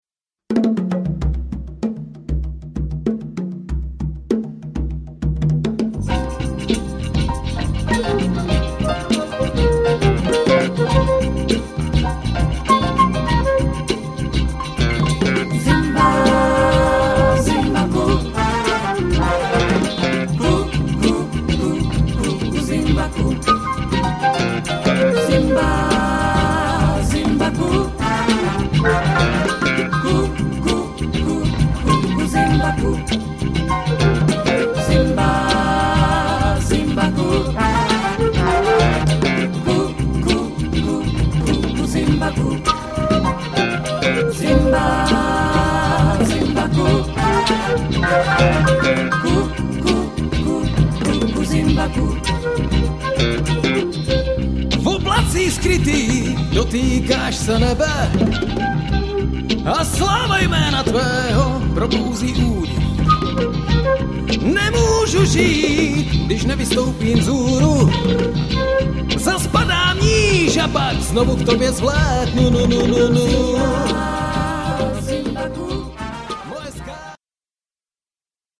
Žánr: pop, folk, jazz, blues.